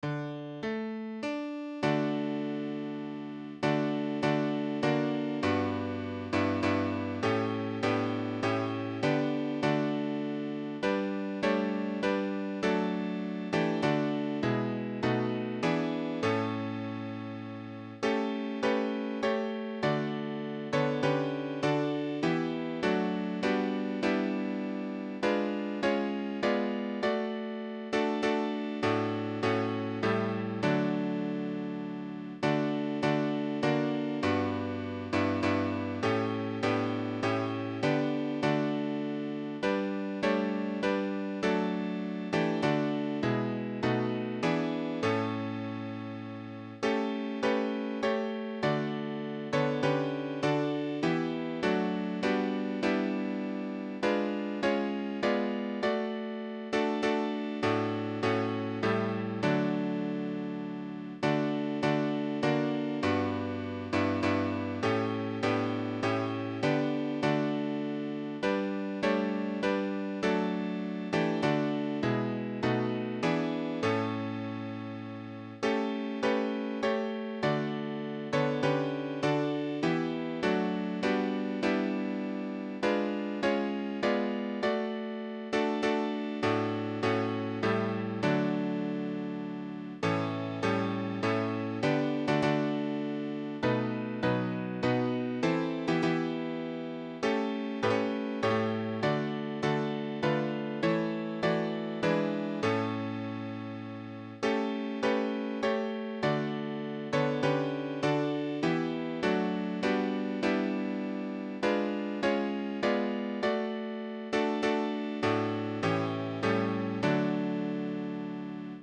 Great-is-Thy-Faithfulness-All-Parts.mp3